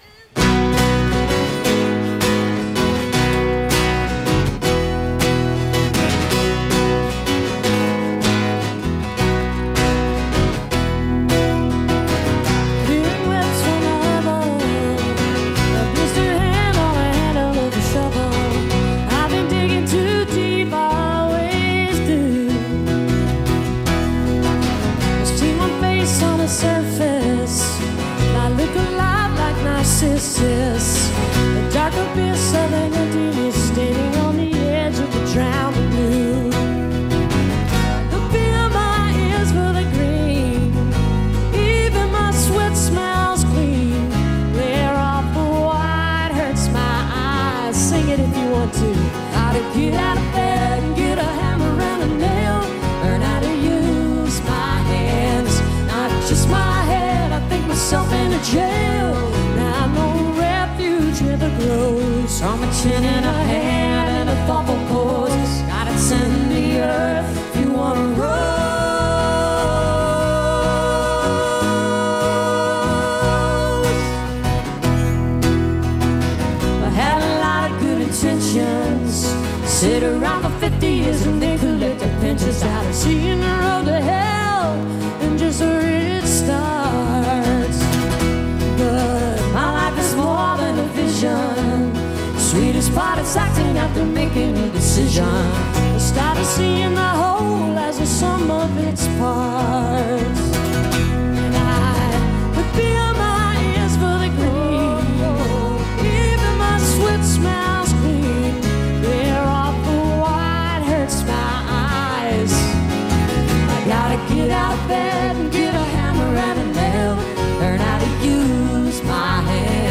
1993-08-21 autzen stadium - eugene, oregon